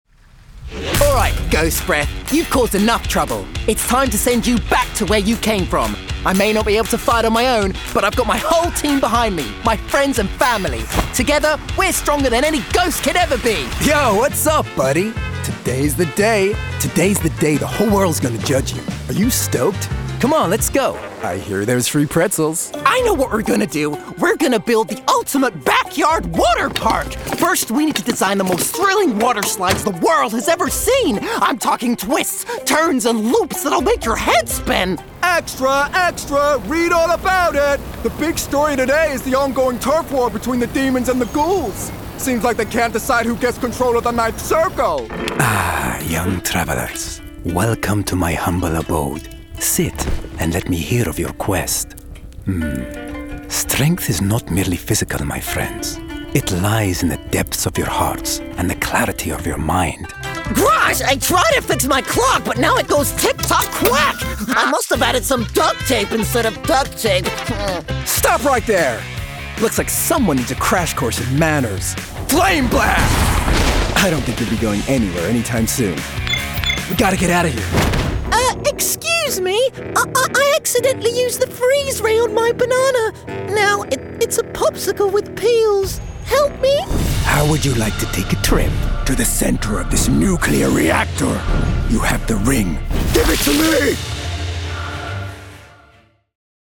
Animation Showreel
Male
Confident
Cool
Friendly